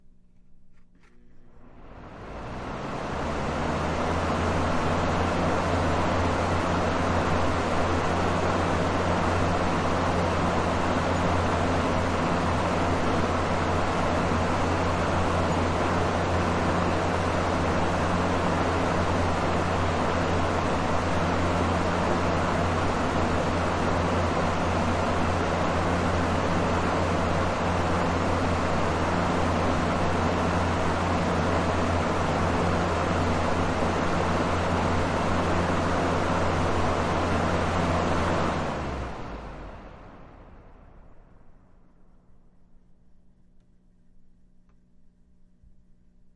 箱式风扇
描述：箱式风扇打开，运行然后关闭。
Tag: 窗口风扇 家庭 箱式风扇 家电 房子 风扇